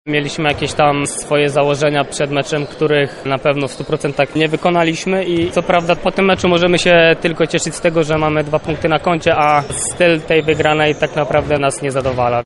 Mówi koszykarz AZS UMCS